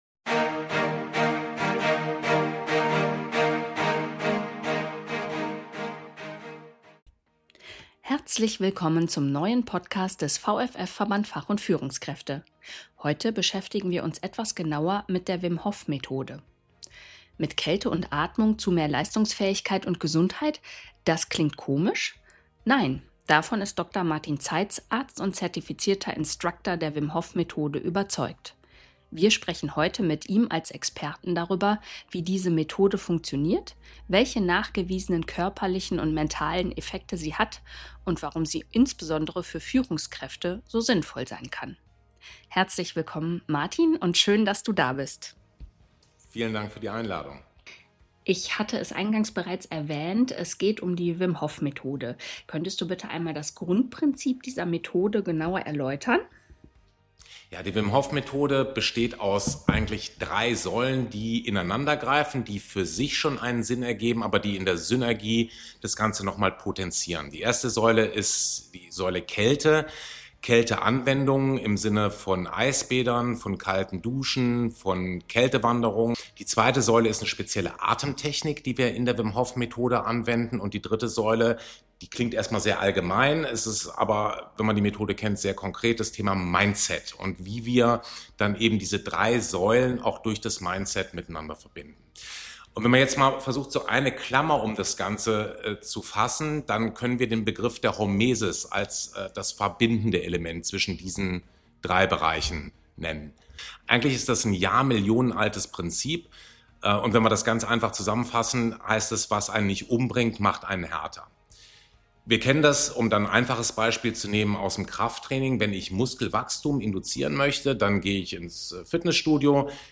Im aktuellen Podcast sprechen wir mit ihm darüber, wie diese Methode funktioniert, welche nachgewiesenen körperlichen und mentalen Effekte sie hat und warum sie insbesondere für Führungskräfte so sinnvoll sein kann.